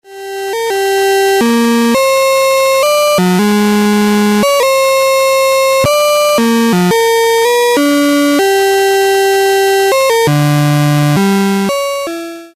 so i did some short and crappy samples of the quantizer.
i quantized a LFO and a S&H using both setups, the 4516s and a 4024 and the latch (D-flipflop). don't care about the metallic sound of the VCO, i did not take care of it when doing the recording using the 4516s back then, so i had to do it with just the same bad sound today using the 4024 to have some comparability.
IMHO the performance of the 4516s is slightly better.